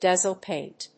dazzle+paint.mp3